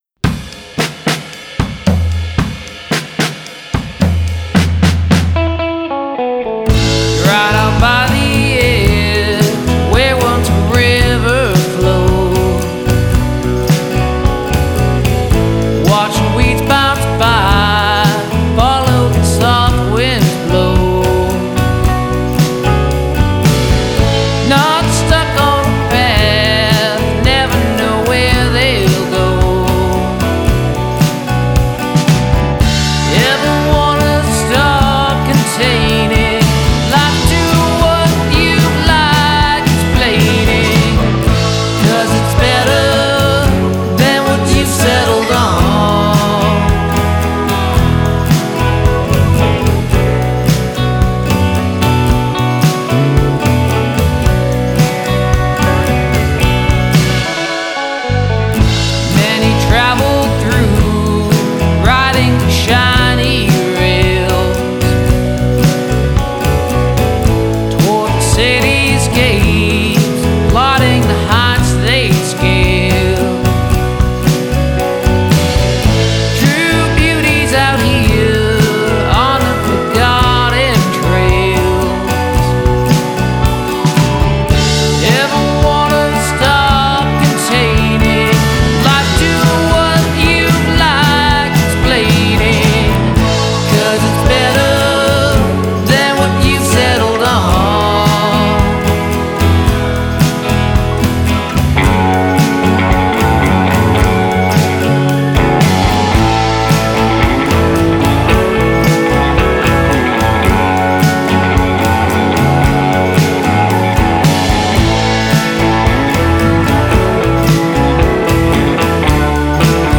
acoustic guitar
electric guitars
bass guitars
drums, percussion
the melody features gorgeous harmonies and pedal steel